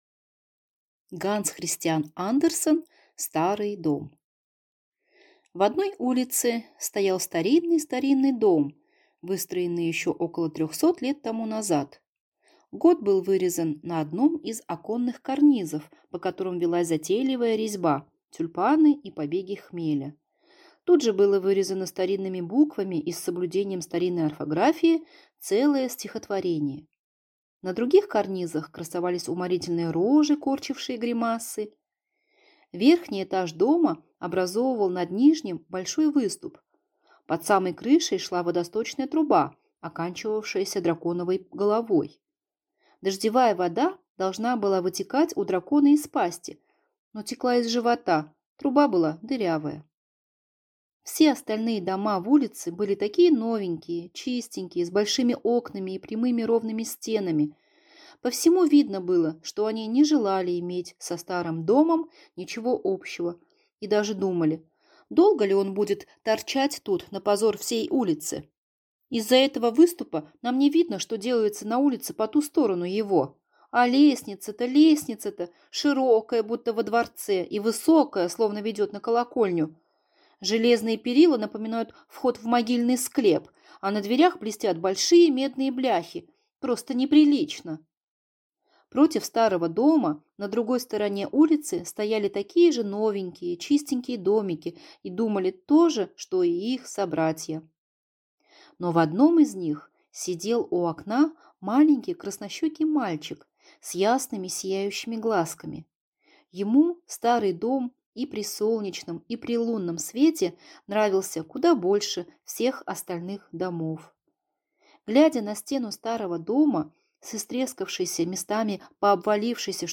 Аудиокнига Старый дом | Библиотека аудиокниг